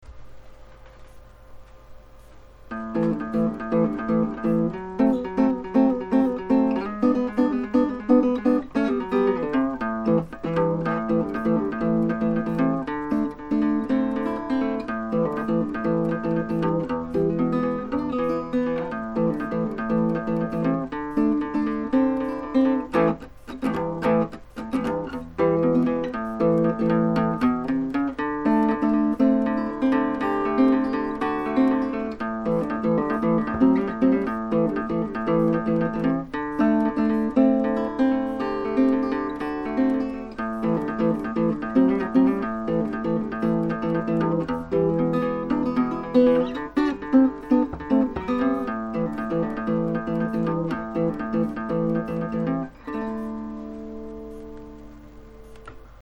Haven't got around to recording yet, cos I can't sing...lol
strummed...kinda like a lead/rhythm hybrid type thing... ;o)
chorus, and I've put an intro on too...